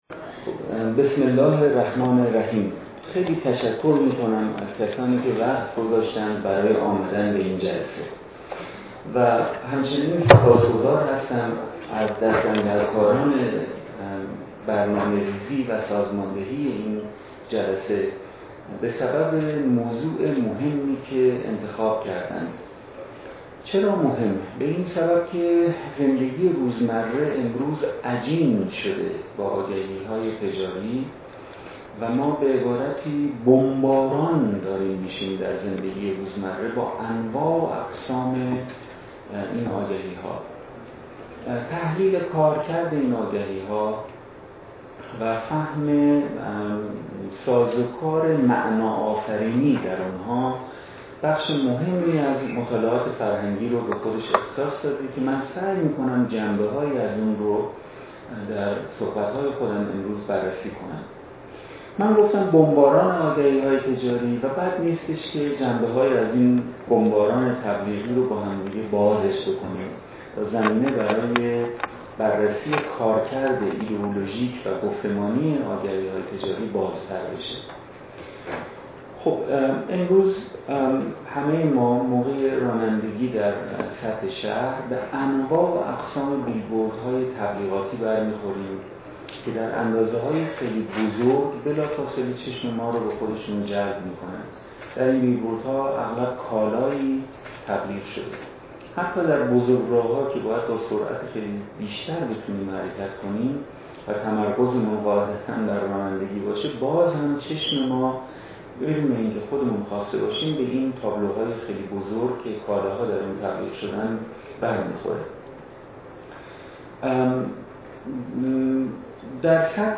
فرهنگ امروز: فایل حاضر سخنرانی «حسین پاینده» در سلسله نشستهای هنر و زندگی روزمره شهری با موضوع «تبلیغات و زندگی روزمره» است که در ۱۲ آذر ماه ۹۳ با همکاری معاونت پژوهشی و آموزشی خانهی هنرمندان ایران و سازمان زیباسازی شهر تهران در خانهی هنرمندان ایران برگزار شد.